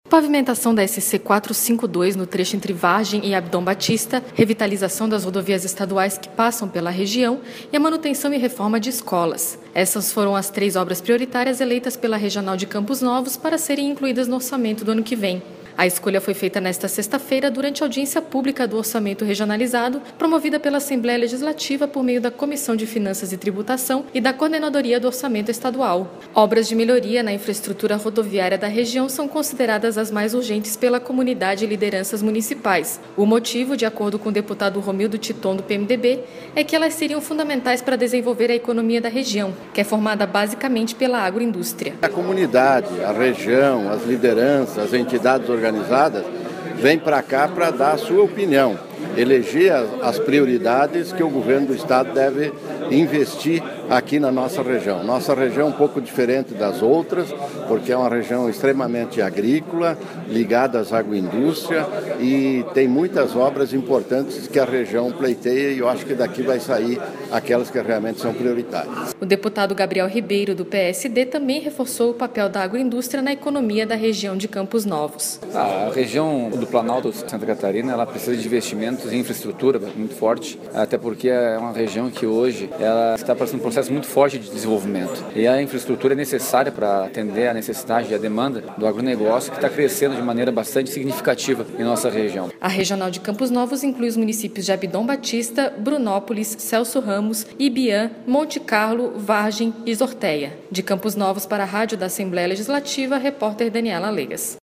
Audiência pública foi realizada na Câmara de Vereadores de Campos Novos e contou com a presença de lideranças da comunidade.
Entrevistas com:
- deputado Romildo Titon (PMDB);
- deputado Gabriel Ribeiro (PSD).